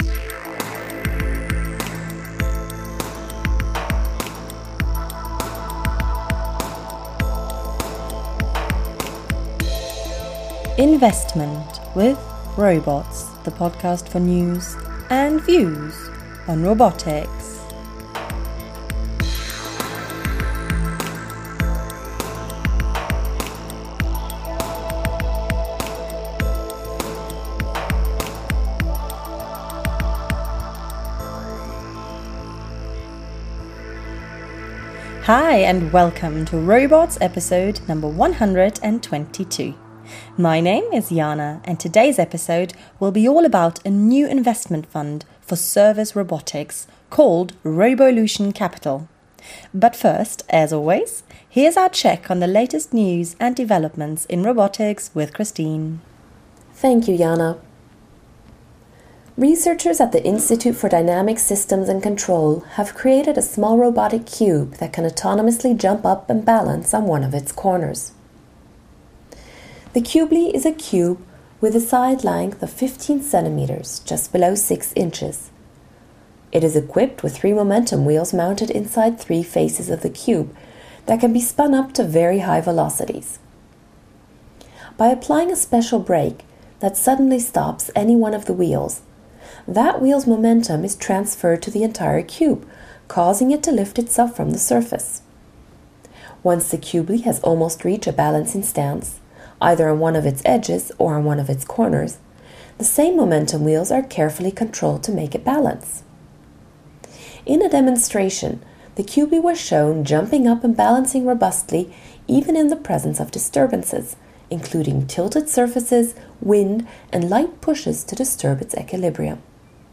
In this interview he shares some thoughts on what he looks for in a potential investment, as well as his views on the European robotics market, vs. that in the US and Asia.